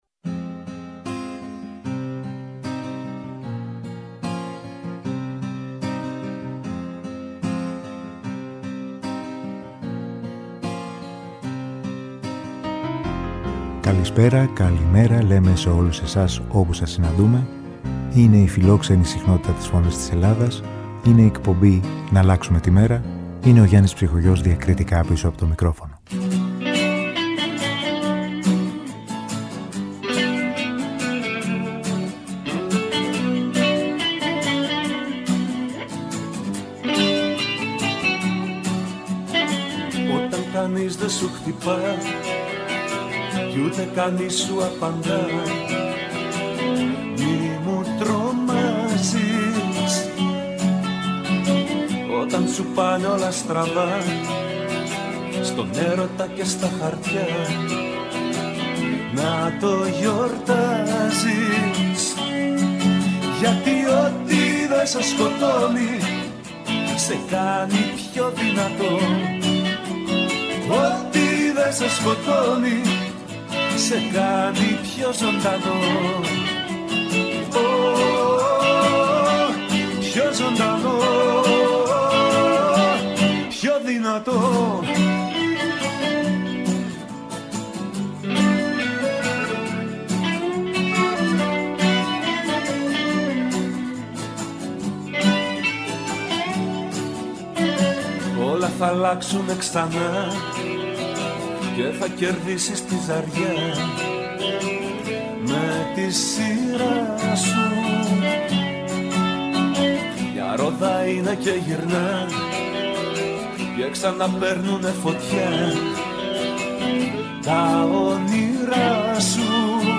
ΜΟΥΣΙΚΗ Μουσική